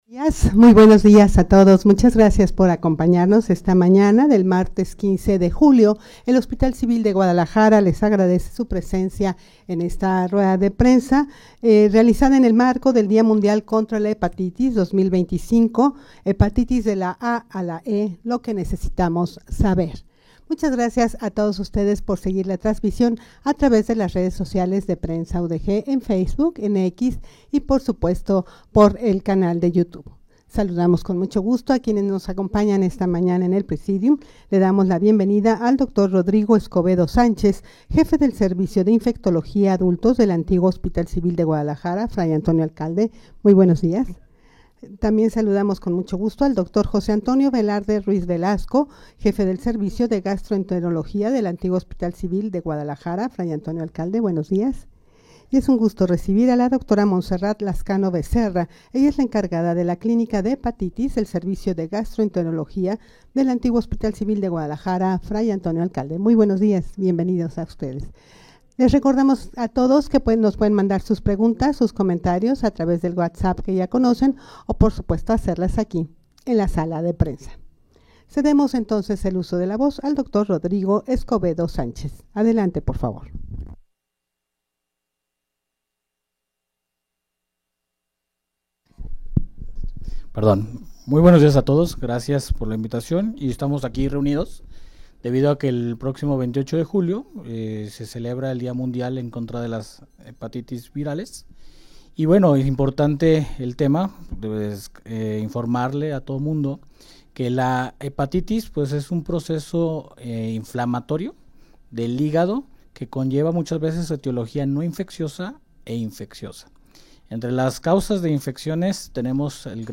Audio de la Rueda de Prensa
rueda-de-prensa-en-el-marco-del-dia-mundial-contra-la-hepatitis-2025-hepatitis-de-la-a-a-la-e.mp3